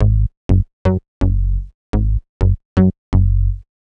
cch_bass_loop_note_125_Gb.wav